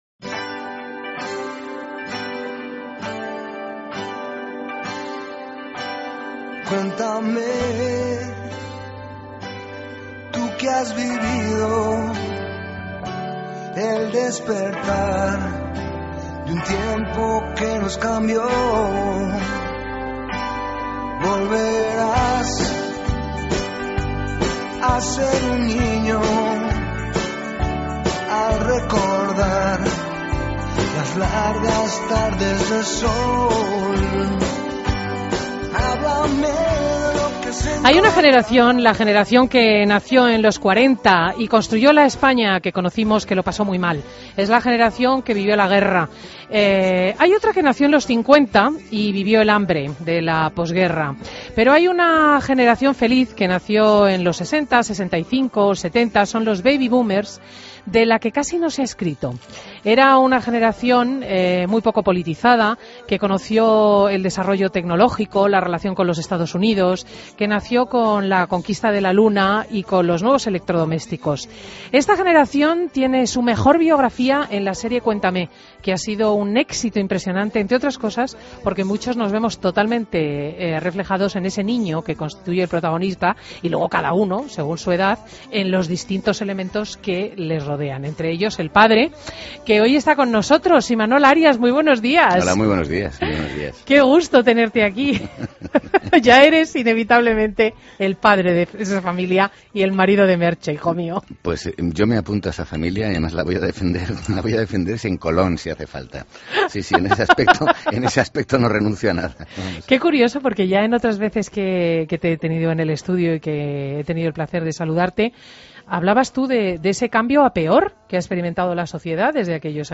AUDIO: Entrevista a Imanol Arias en Fin de Semana COPE